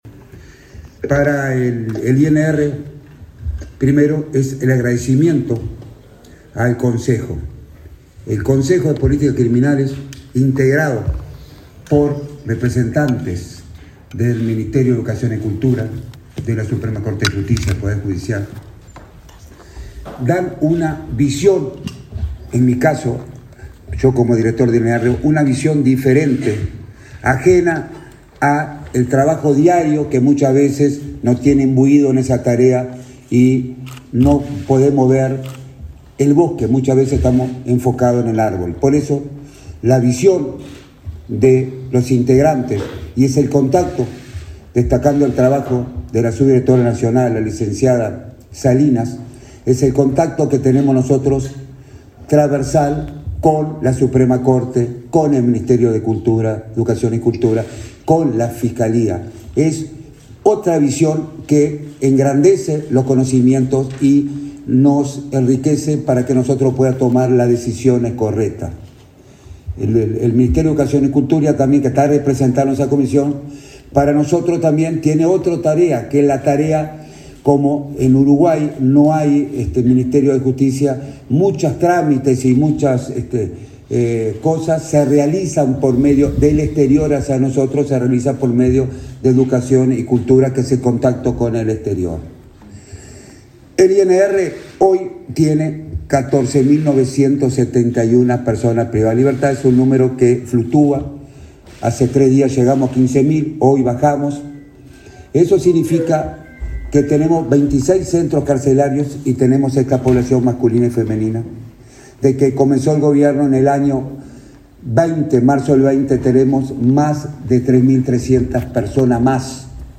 Palabras de autoridades en seminario académico
Palabras de autoridades en seminario académico 09/08/2023 Compartir Facebook X Copiar enlace WhatsApp LinkedIn Este miércoles 9, el director del Instituto Nacional de Rehabilitación (INR), Luis Mendoza, y el secretario general de la Junta Nacional de Drogas, Daniel Radío, participaron en un seminario académico sobre consumo problemático de drogas en personas privadas de libertad, realizado en el Palacio Legislativo.